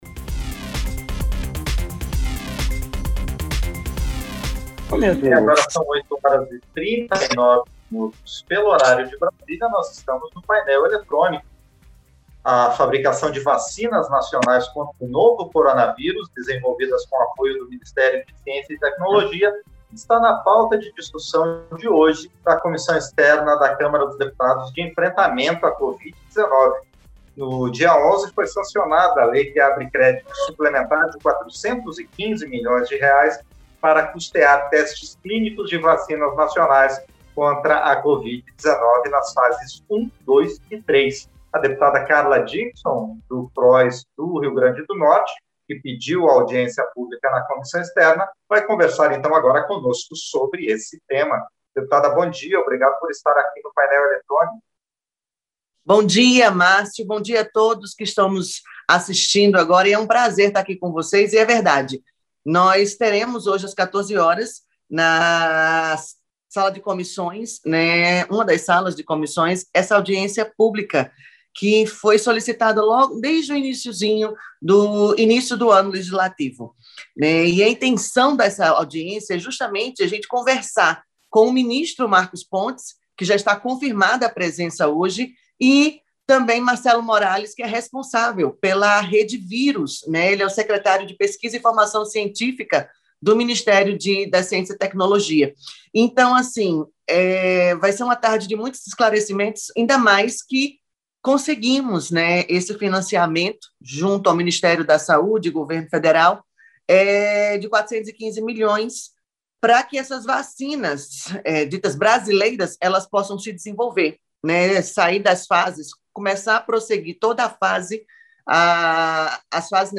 Entrevista - Dep. Carla Dickson (Pros-RN)